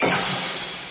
Sword.wav